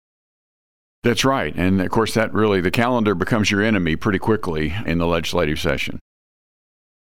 3. Senator Emery also says Senate Bill 7 would modify provisions of civil procedure regarding joinder and venue.